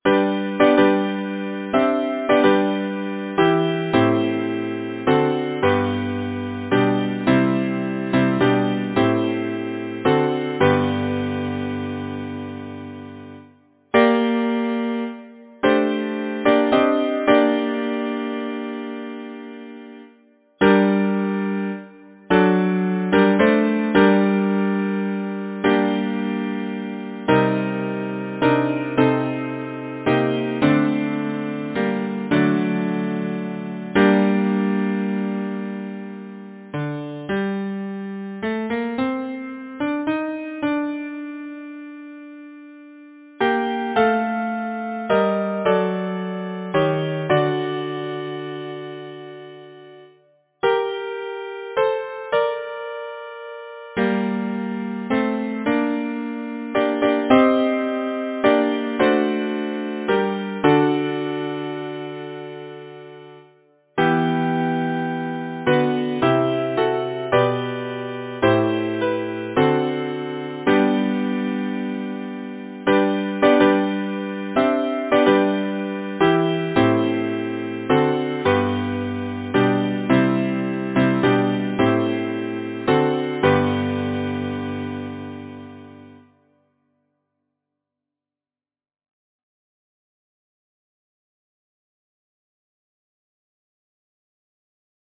Title: Bonnie Sweet Robin Composer: Henry Kimball Hadley Lyricist: William Shakespeare Number of voices: 4vv Voicing: SATB Genre: Secular, Partsong
Language: English Instruments: A cappella